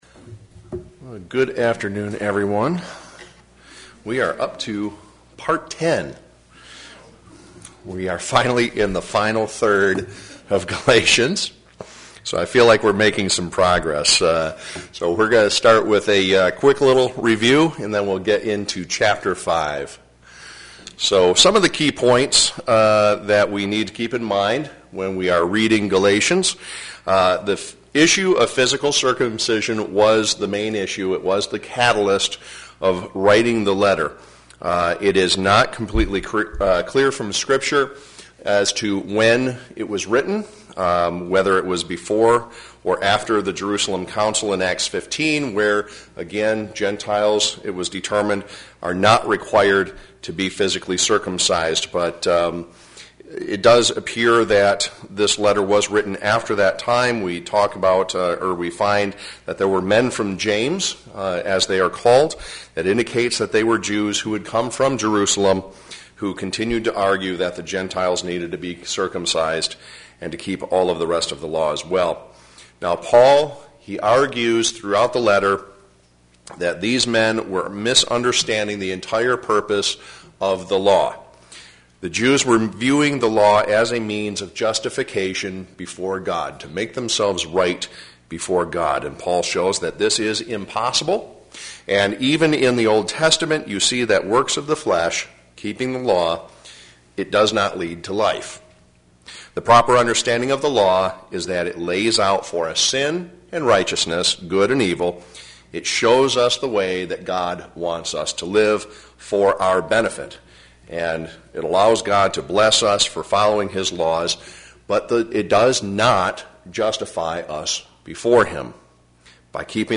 Galatians Bible Study: Part 10